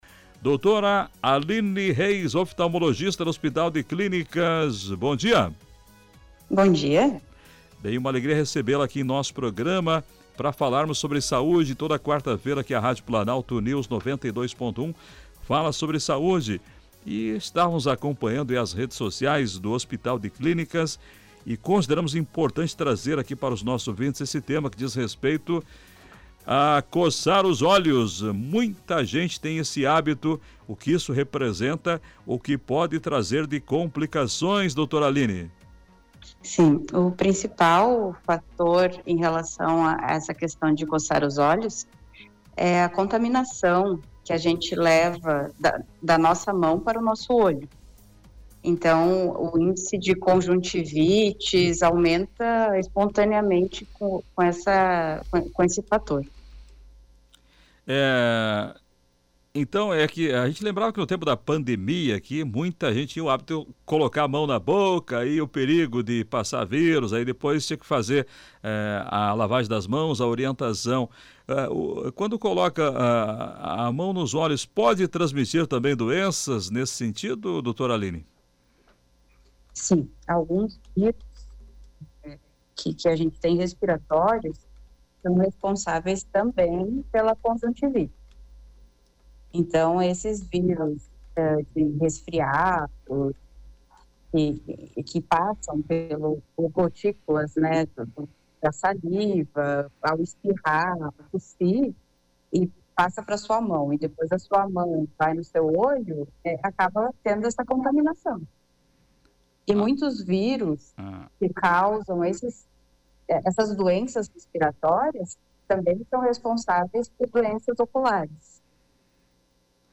O tema foi tratado em entrevista no programa Comando Popular, da Rádio Planalto News (92.1).